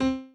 b_pianochord_v100l16o5c.ogg